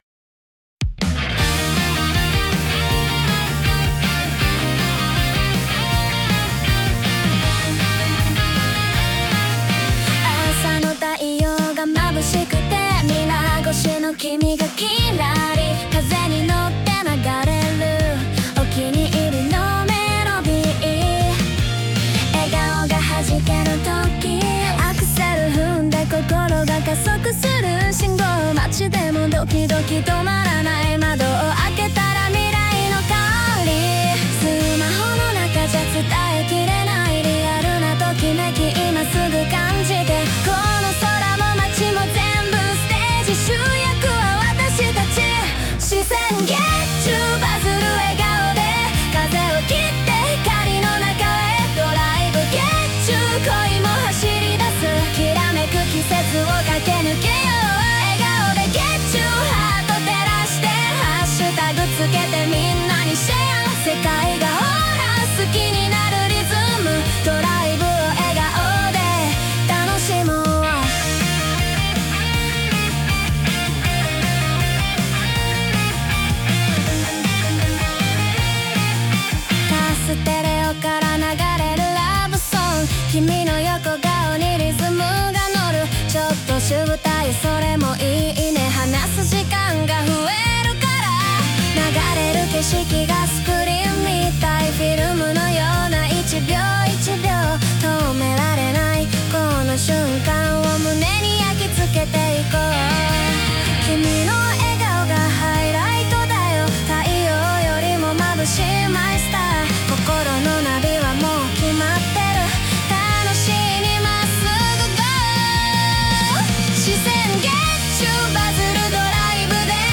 — ステージのきらめきを感じるアイドルチューン